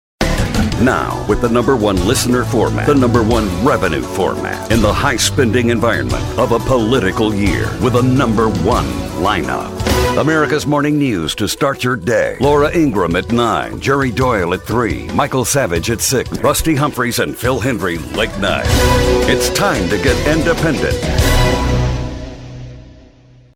News Talk News Talk More Info Close